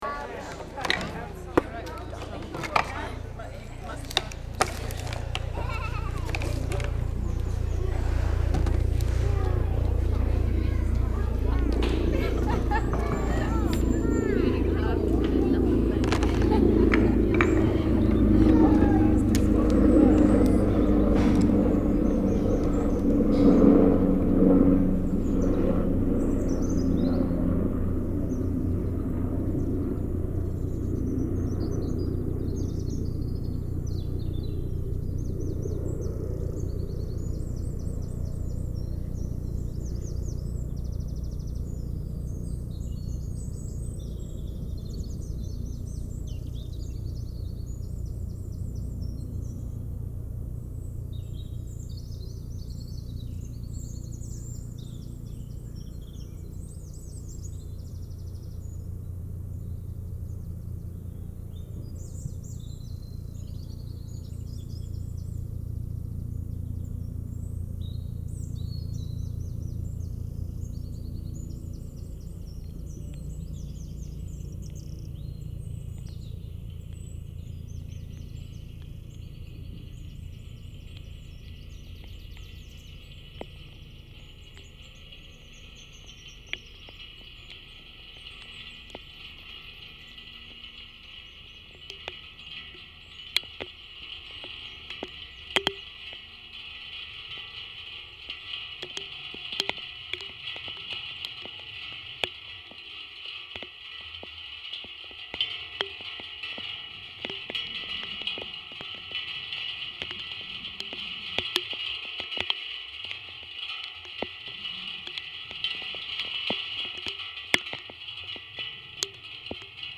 sound descriptors: a list of sounds as they appear on the winter walk
Tales of winter, dawn on winter solstice, dawn on Christmas Day, carrion caw, footfall on frosted boards, ice cracking, snow melting, gate opening, footsteps on snow, melting ice, mini-stream flowing, wire in the river, water hole, river hatch, children pond-dipping, pond dripping, chalk sample releasing Co2, smoke, crackle and flame, branch on fire, fire-break, willow weaving, Chinook drone, rain on wire, rain on gate, sawing wood, tying bundles, shower of woodpile, mending fences, pulling nails, chainsaw, wood piling, walking through a puddle, a barn door closed.
The project began in the winter of 2010, with the aim of creating a soundwalk based on recordings made in the Winnall Moors conservation reserve over the period of one year.
Field Recording Series by Gruenrekorder
winter_rain_with_helicopter.mp3